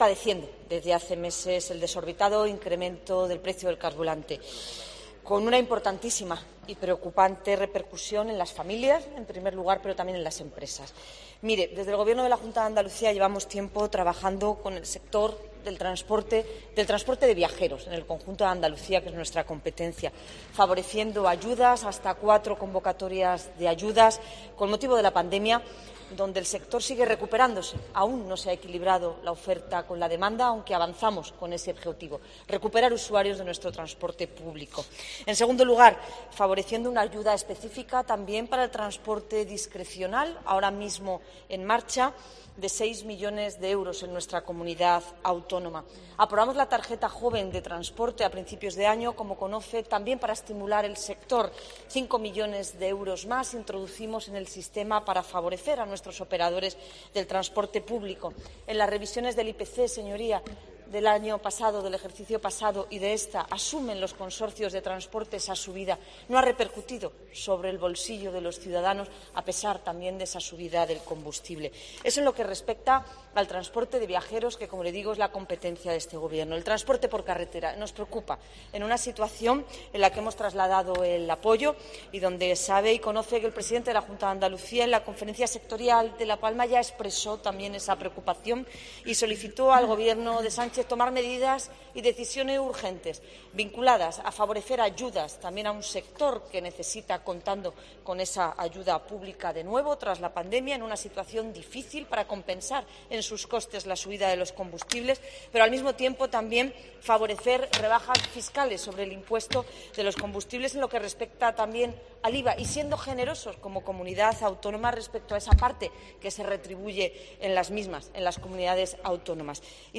“El transporte por carretera es un sector estratégico que demanda una respuesta eficaz para garantizar su supervivencia”, ha remarcado durante su intervención en el Pleno del Parlamento.